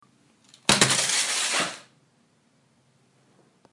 地板上的笔记本滑轨 " 笔记本滑轨2
描述：滑在水泥地板上的膝上型计算机
Tag: 膝上型刮 笔记本电脑的滑动层 笔记本电脑的滑动